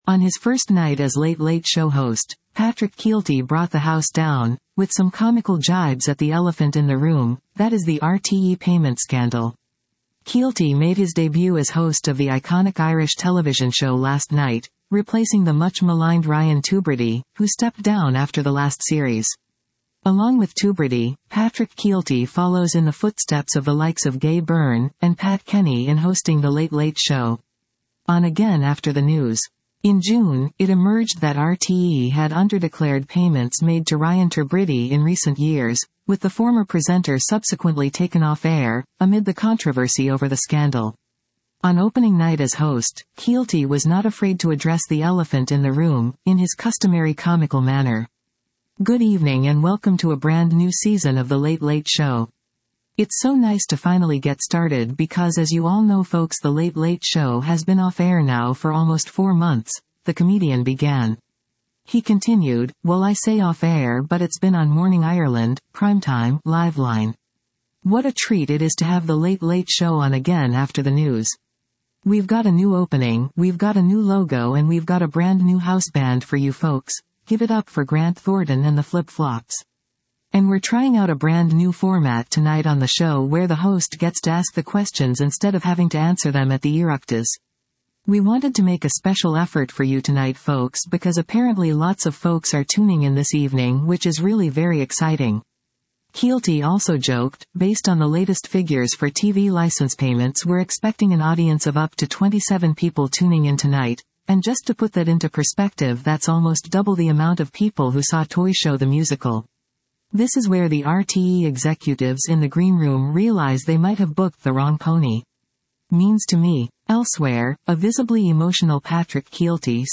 On his first night as Late Late Show host, Patrick Kielty brought the house down, with some comical jibes at the elephant in the room, that is the RTÉ payment scandal.